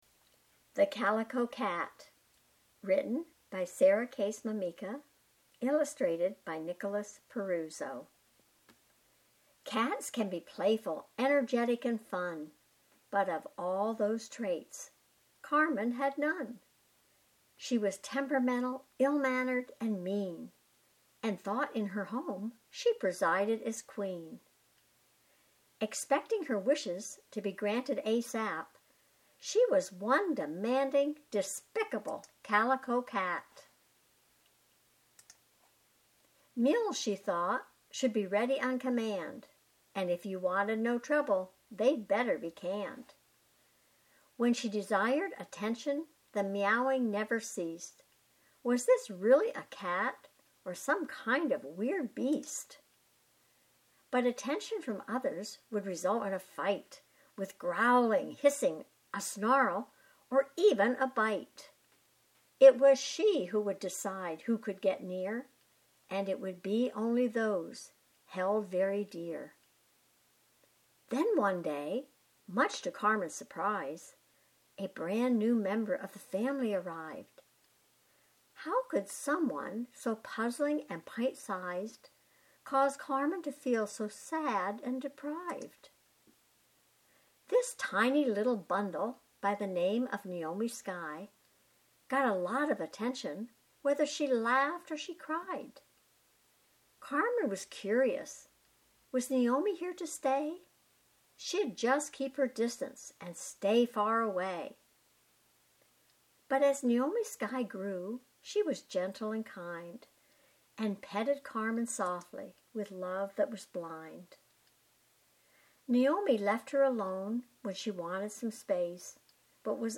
Reading The Calico Cat